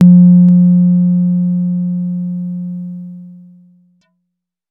REDD PERC (19).wav